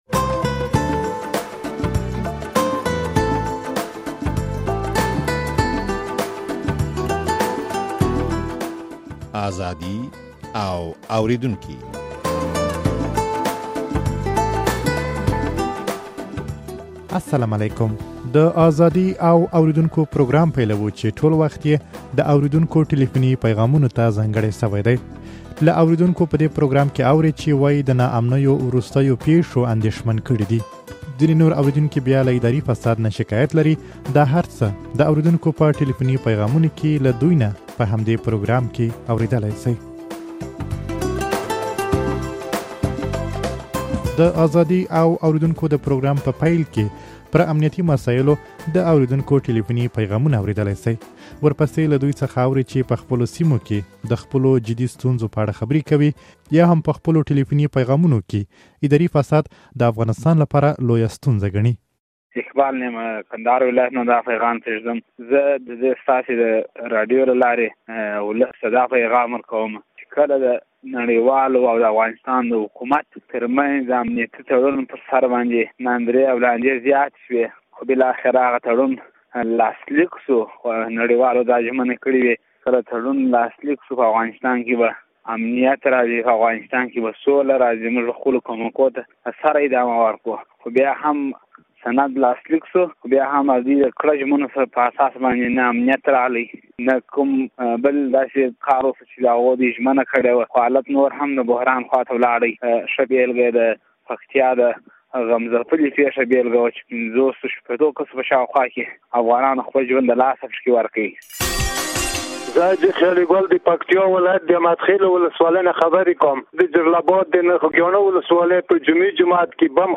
د ازادي او اورېدونکو پروګرام پيلوو، چې ټول وخت يې د اورېدونکو ټليفوني پيغامونو ته ځانګړى شوى دى.